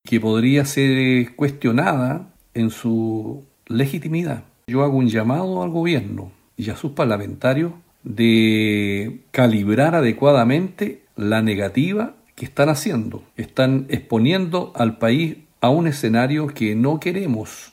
Es por eso que el integrante de la instancia, el senador Francisco Huenchumilla, señaló que sin escaños reservados la convención pierde legitimidad y llamó al oficialismo a ceder en las negociaciones.